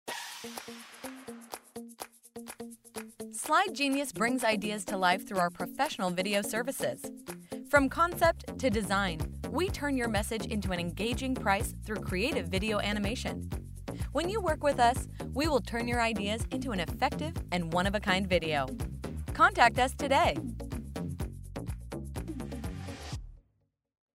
Voice over examples
Female Voice 4